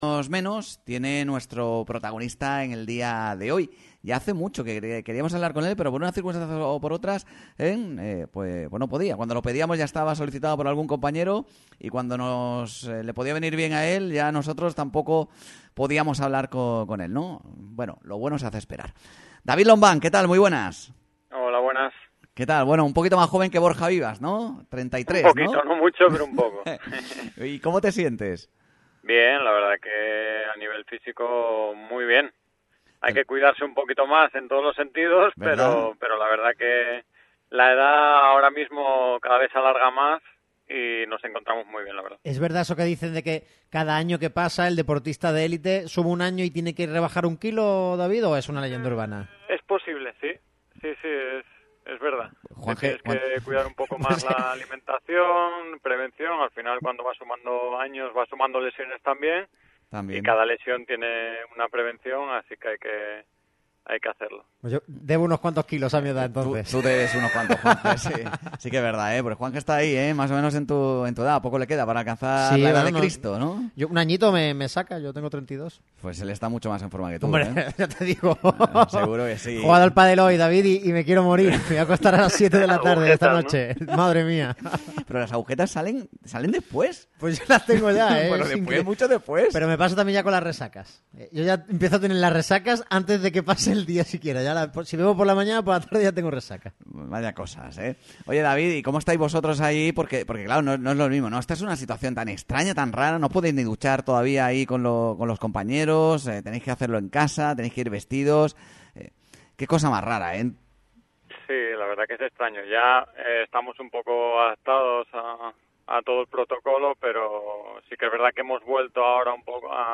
David Lombán elogió en directo para Radio MARCA Málaga a Manolo Gaspar, quien ha renovado con el club hasta 2023: "Se lo ha ganado"